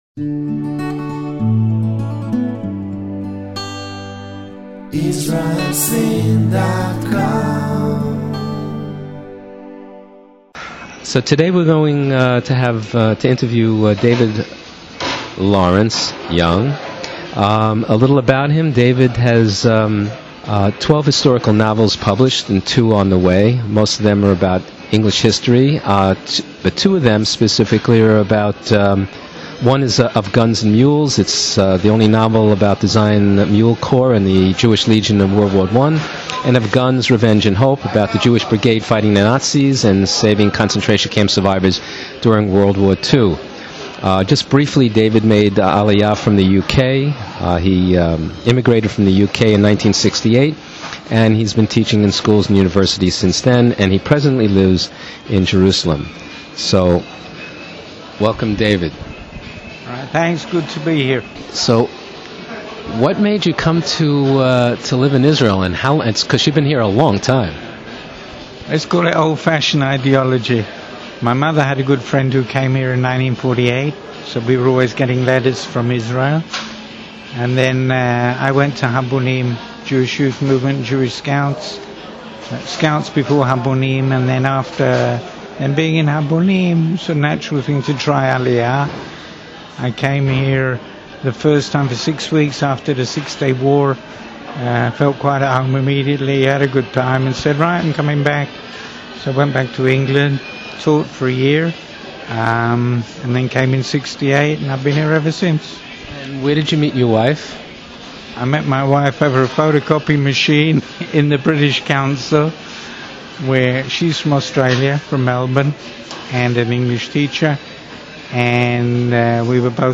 Israel Seen Interviews Israeli Author